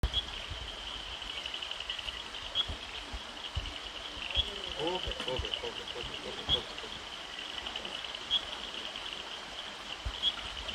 Scientific Name: Raorchestes Griet Common Name: Munnar Common Bush Frog